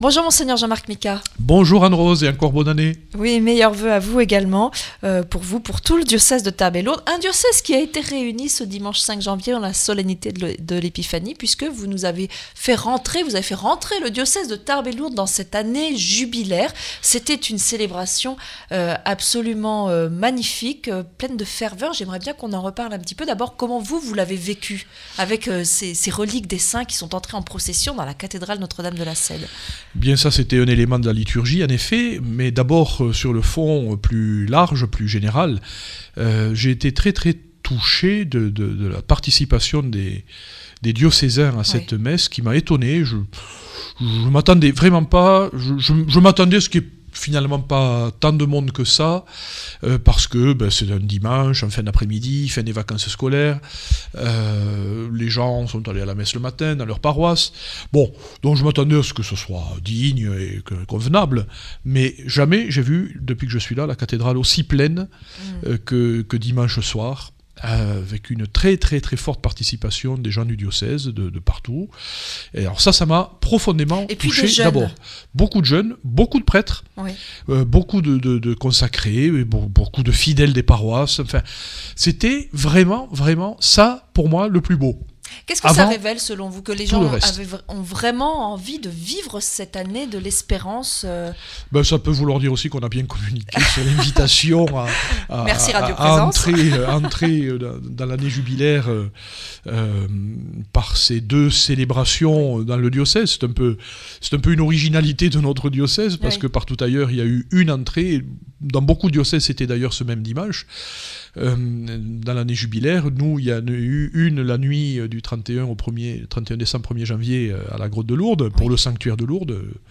Entretien avec Mgr Micas - Évêque de Tarbes Lourdes du 10 janv.